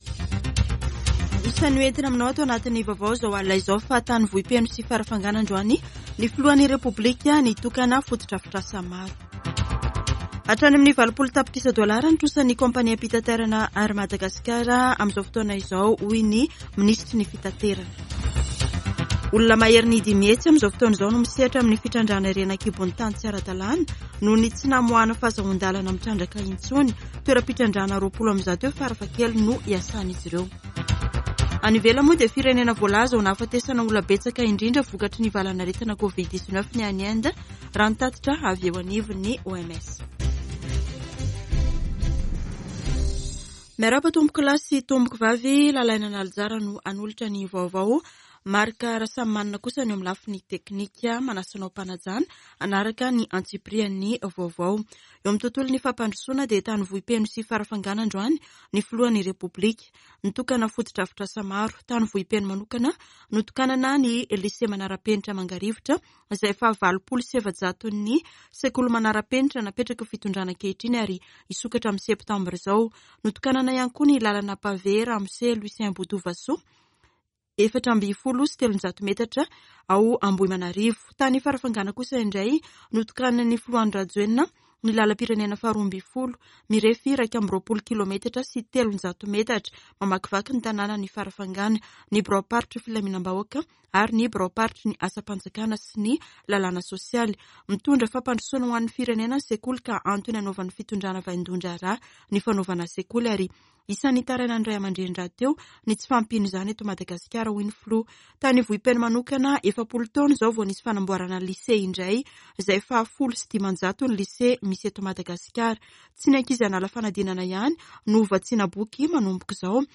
[Vaovao hariva] Zoma 06 mey 2022